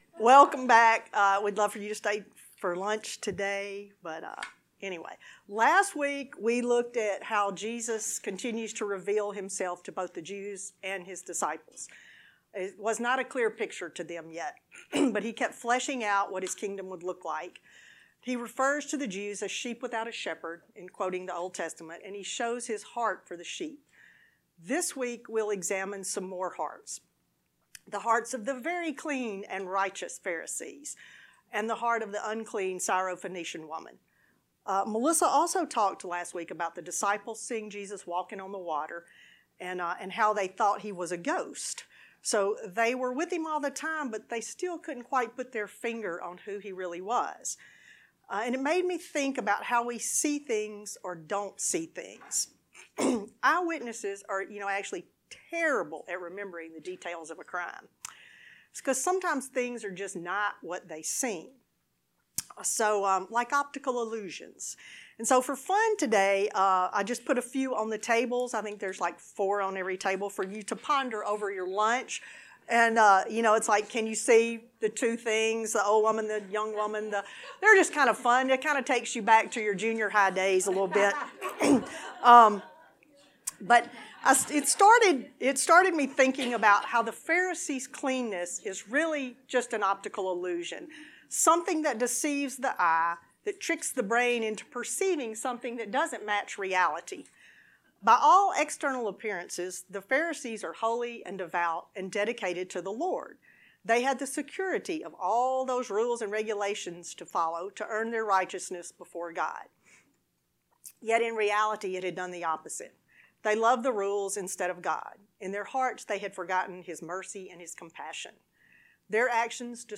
Lesson 10